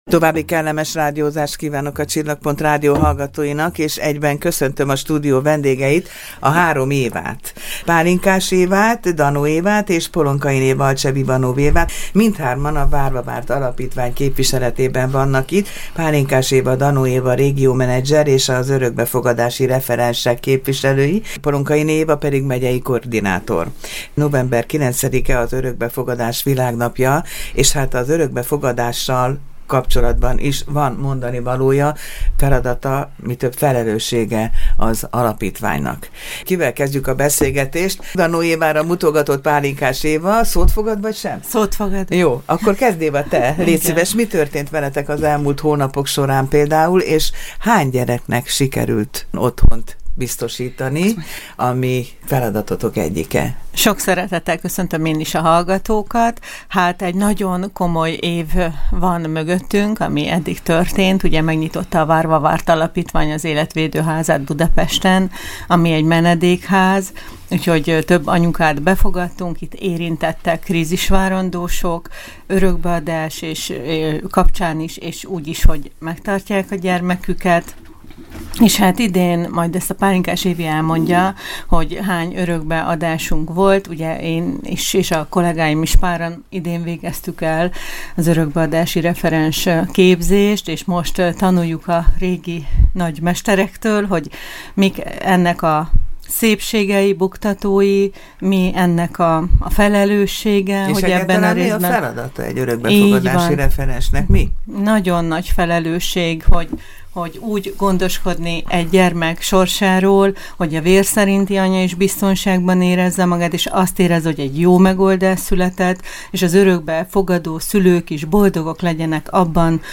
beszélgetett a Csillagpont Rádió műsorában